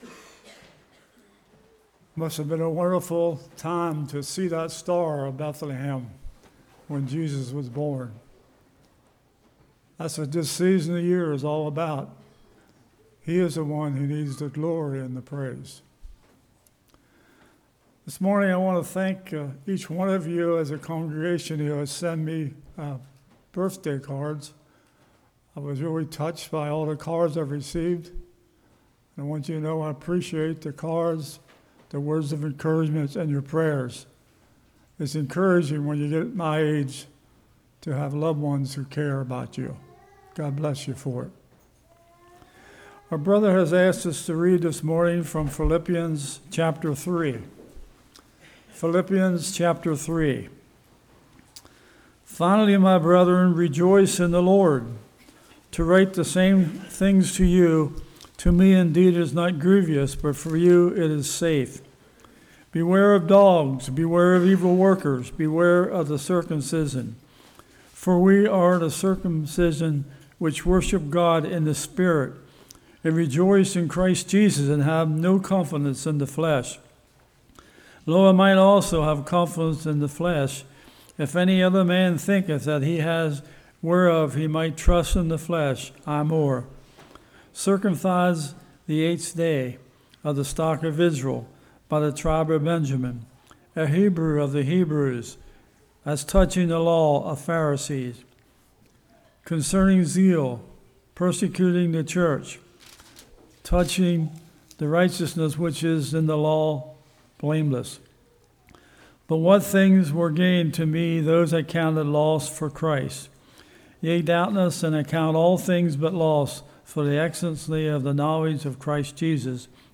Philippians 3:1-14 Service Type: Morning Value of earthly things Secret of Satisfaction from Paul What about us?